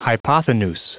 Prononciation du mot hypothenuse en anglais (fichier audio)
Prononciation du mot : hypothenuse